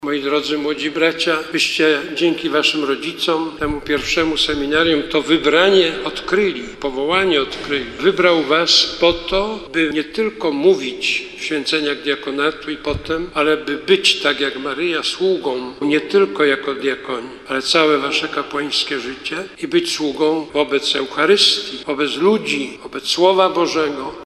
Po święceniach kardynał Nycz zaapelował do nowych diakonów, aby stali się sługami na wzór Matki Bożej.